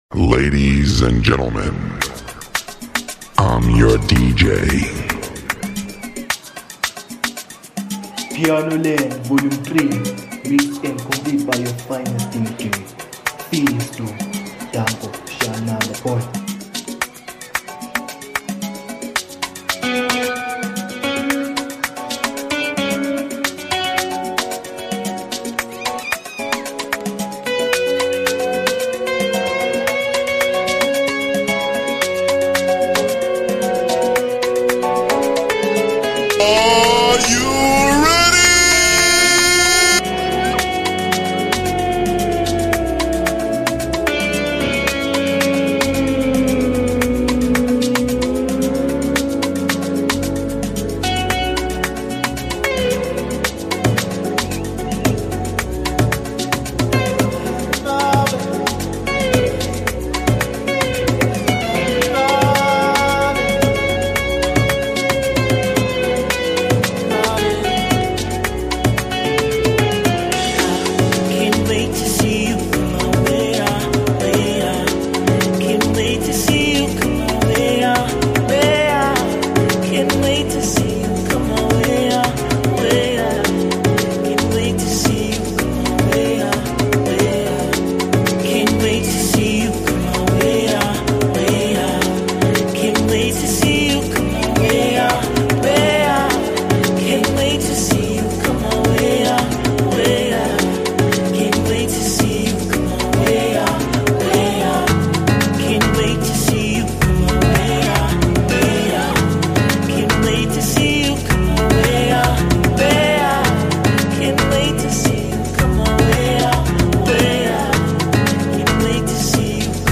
We have a new Amapiano mixtape